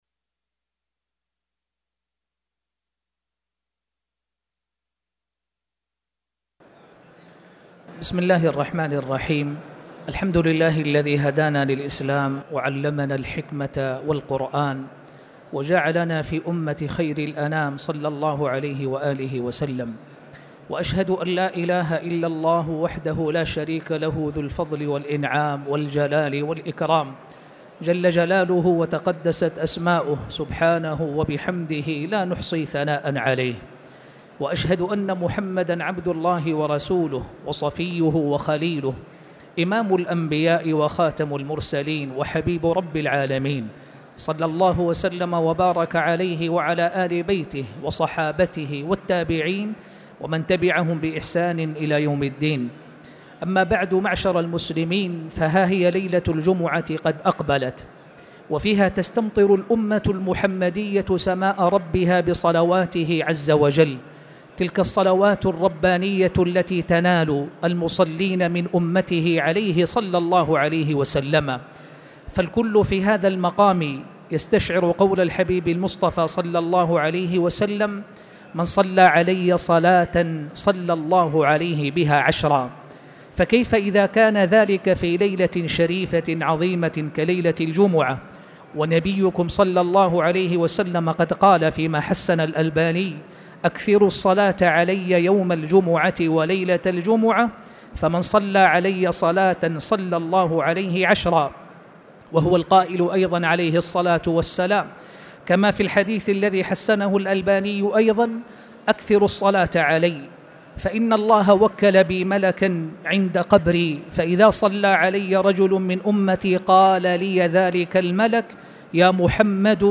تاريخ النشر ١١ ذو القعدة ١٤٣٨ هـ المكان: المسجد الحرام الشيخ